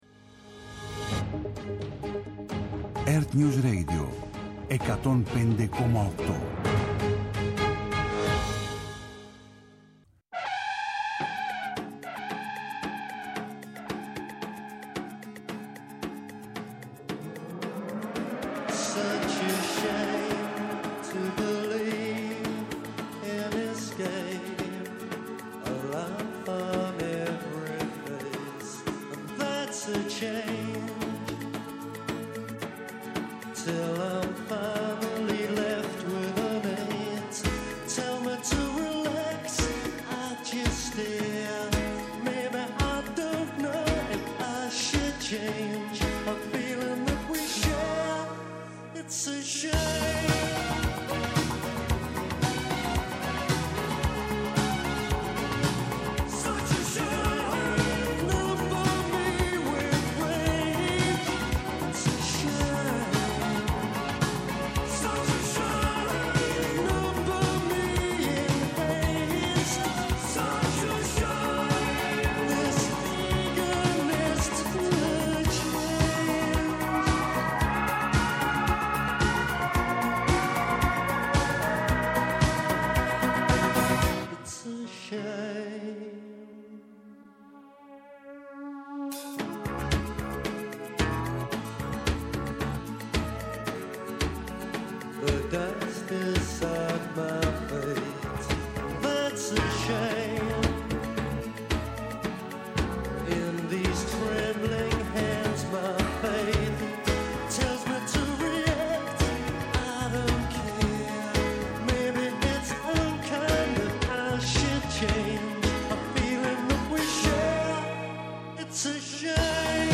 για την απευθείας μετάδοση των δηλώσεων του Αμερικανού Υπουργού Εξωτερικών Μάρκο Ρούμπιο από την Ουάσινγκτον.
Νυχτερινές ιστορίες με μουσικές και τραγούδια που έγραψαν ιστορία.